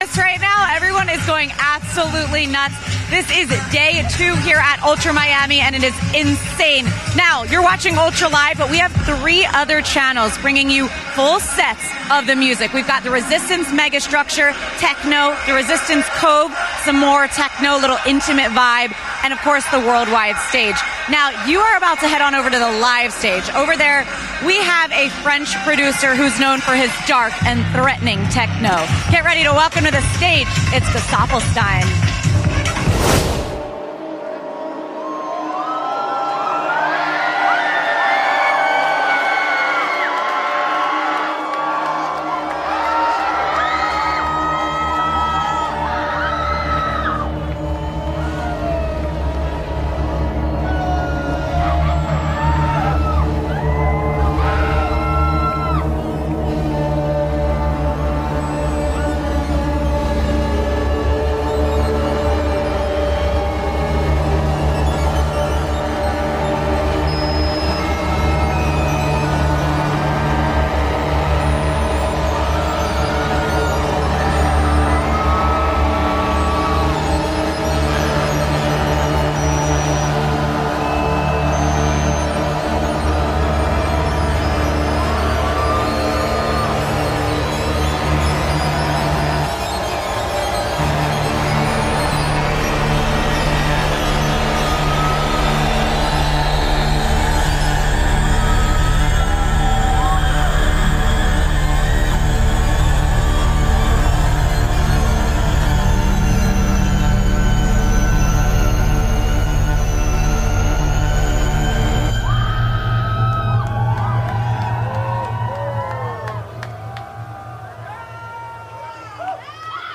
DJ mix or Live Set